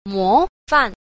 4. 模範 – mófàn – mô phạm